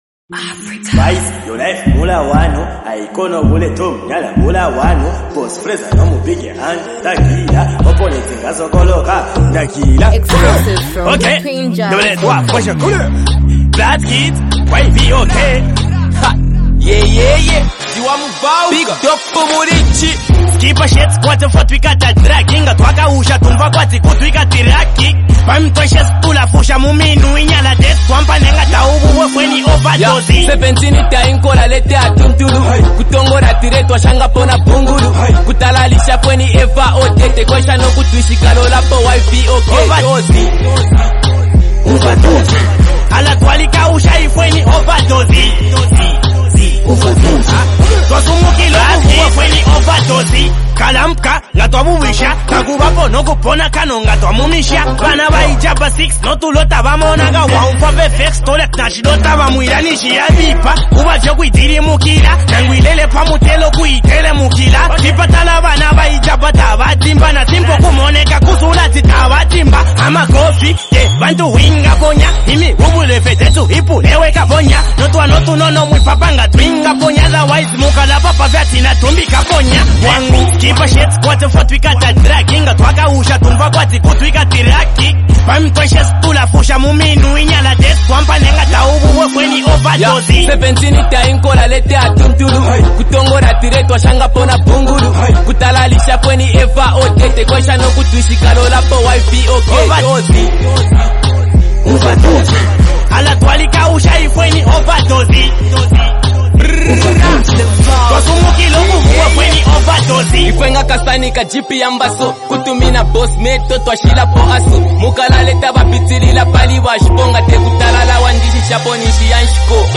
a hard-hitting street anthem
leads the track with confident and aggressive verses
complements the song with a bold and energetic verse